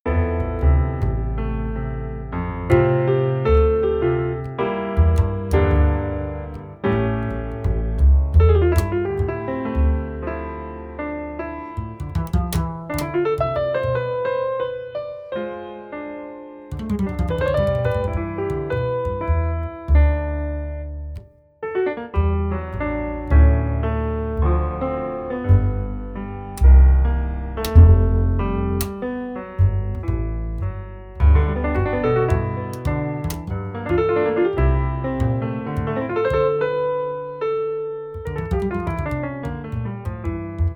Piano jazz (bucle)
melodía
repetitivo